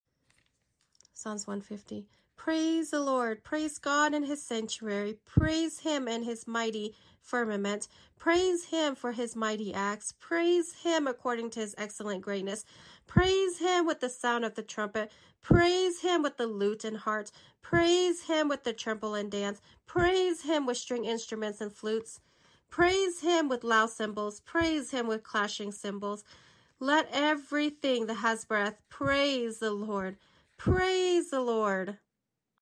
😮 Last Psalms reading posting here. See my other videos for the rest of the Psalms.